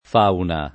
DOP: Dizionario di Ortografia e Pronunzia della lingua italiana
vai all'elenco alfabetico delle voci ingrandisci il carattere 100% rimpicciolisci il carattere stampa invia tramite posta elettronica codividi su Facebook fauna [ f # una ] s. f. — con F‑ maiusc. come nome della dea romana